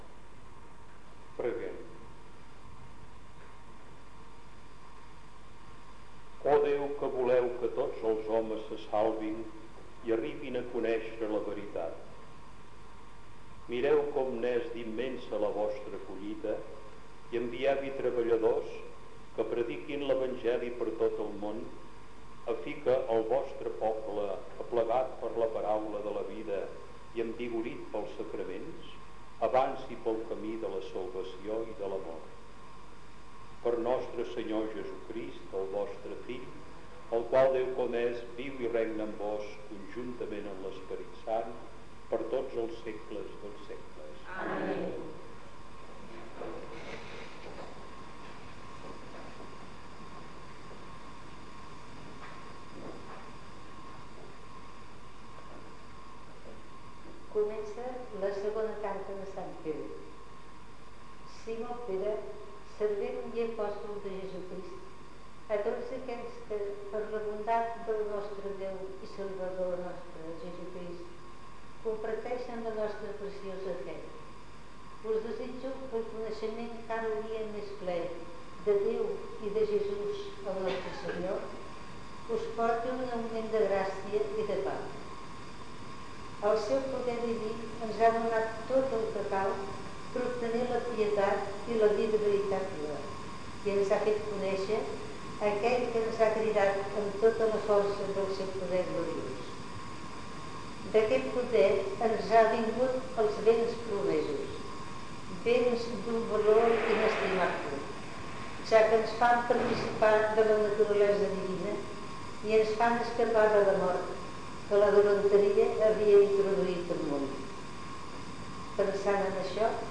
Corpus Oral de Registres (COR). REL3. Missa
Aquest document conté el text REL3, una "missa" que forma part del Corpus Oral de Registres (COR).